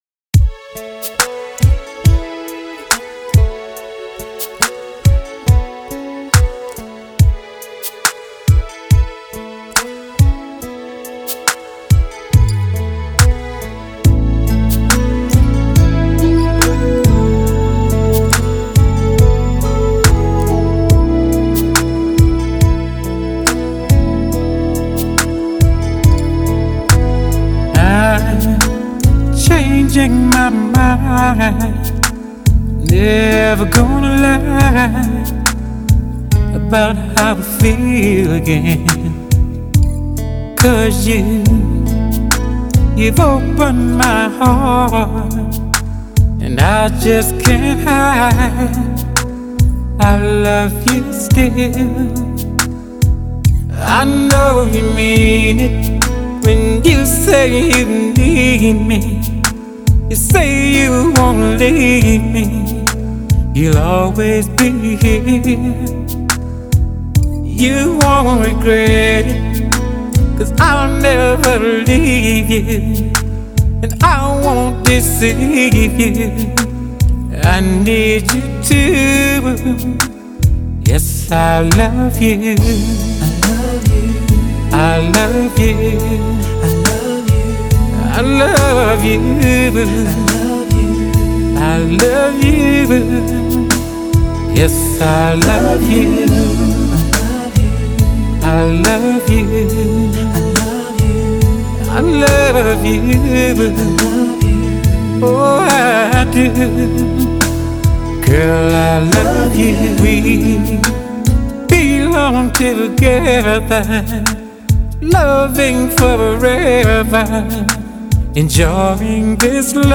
唱片类型：爵士乡村